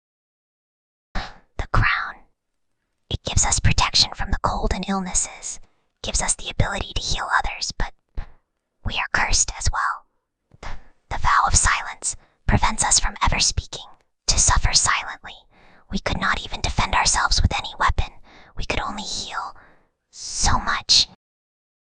Whispering_Girl_9.mp3